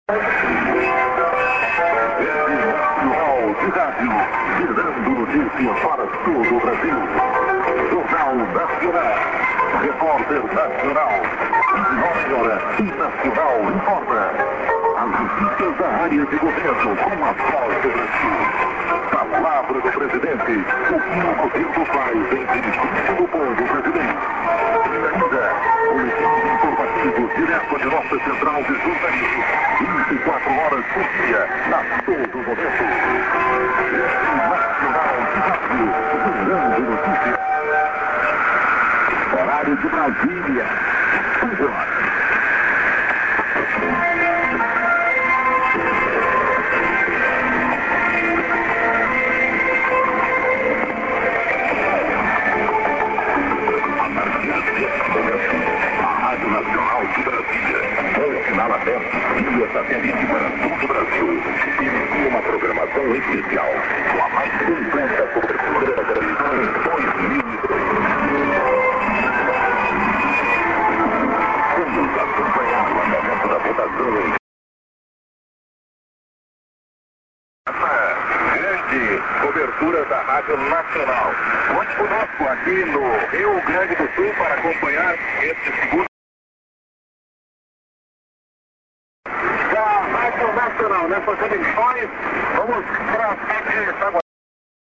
ID(man)->no TS->ID:R.Nac.Blazil(man)->ID(man)->ID(man)　＊途中をカットしてＩＤだけを